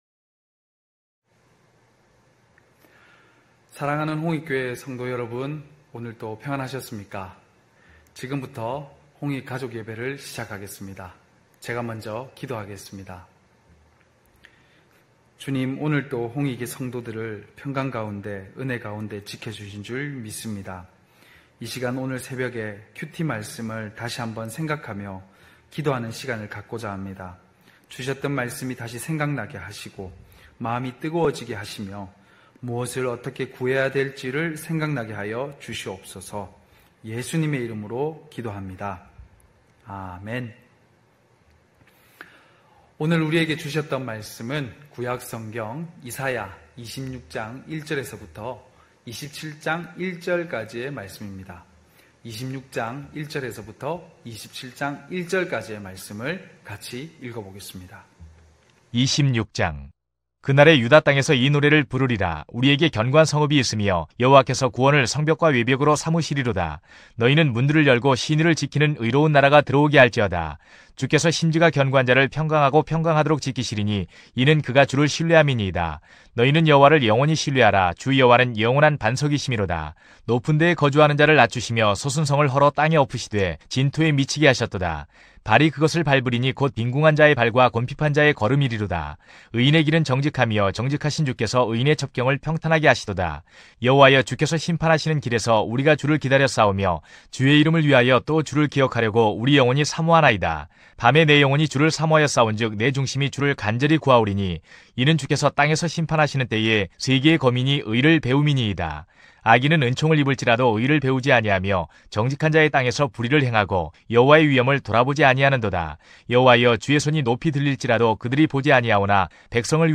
9시홍익가족예배(8월14일).mp3